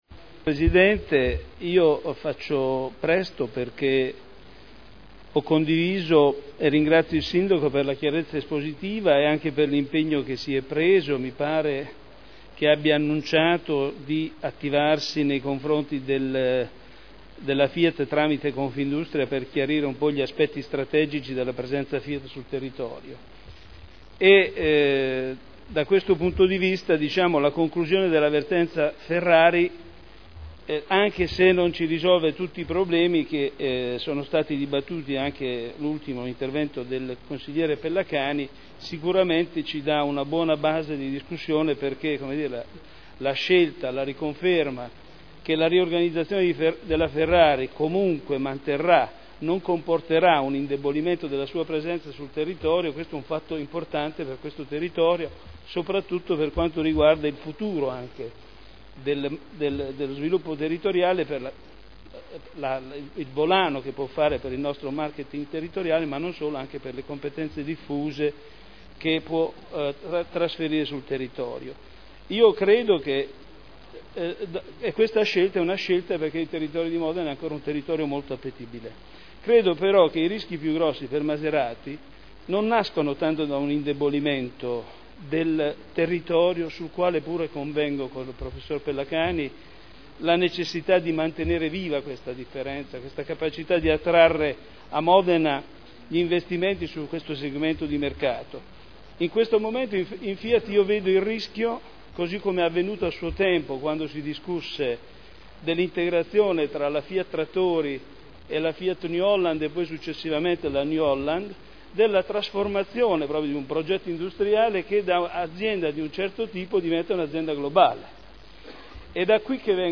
Michele Andreana — Sito Audio Consiglio Comunale